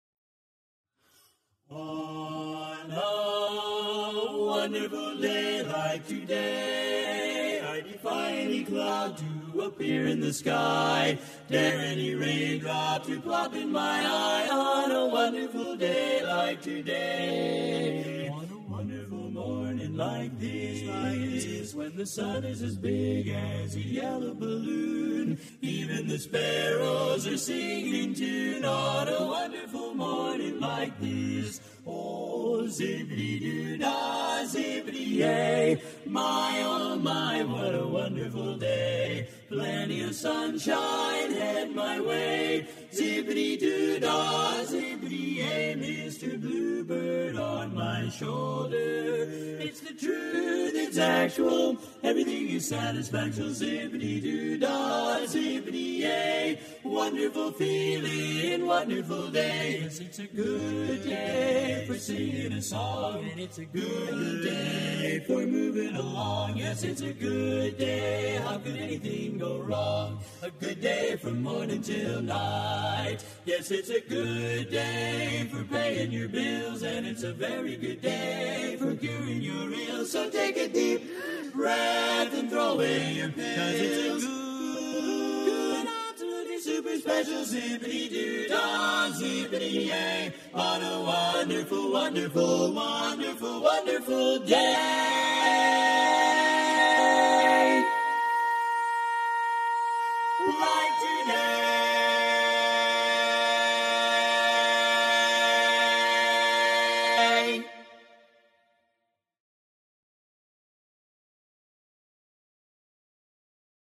G Major
Full Mix